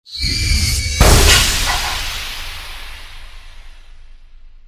NetSlam.wav